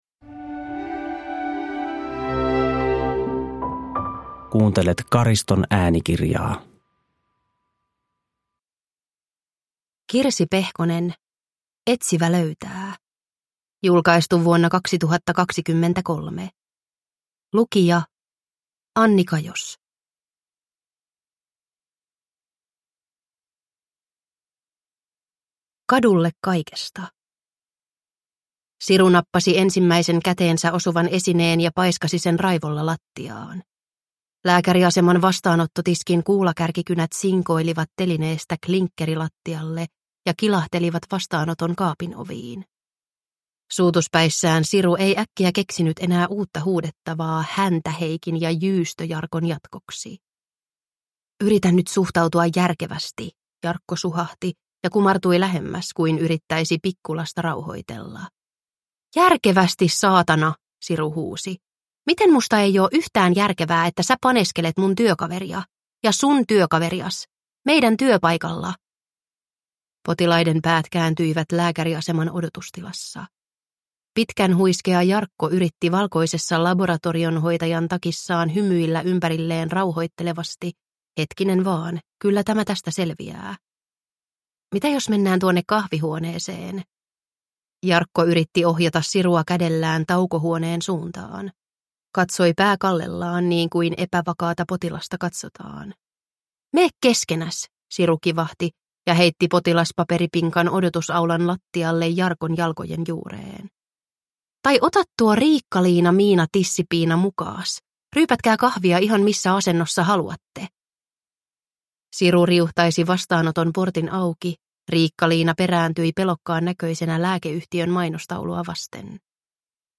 Etsivä löytää – Ljudbok – Laddas ner